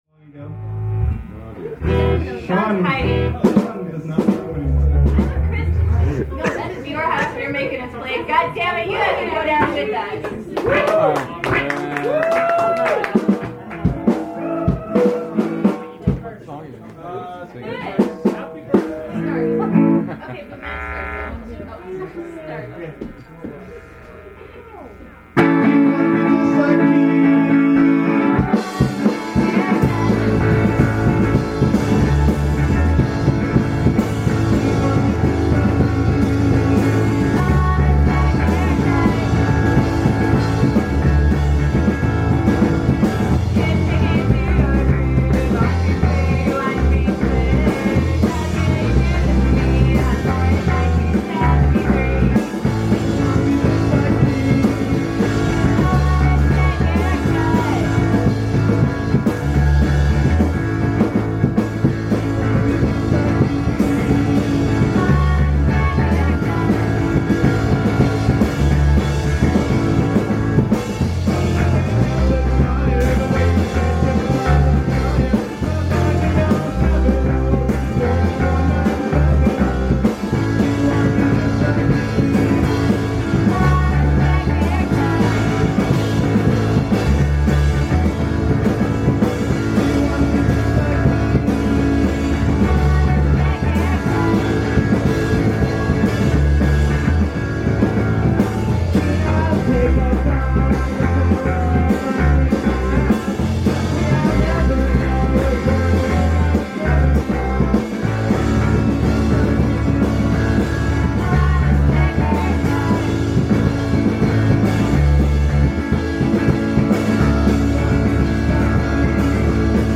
indiepop superstars
(Both of these MP3s are live recordings from that show).